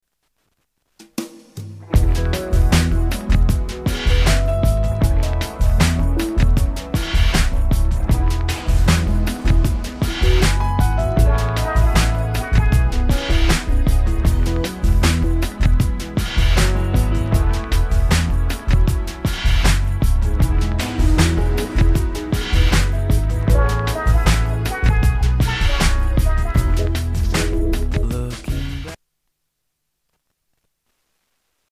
STYLE: Pop
simple pop sounds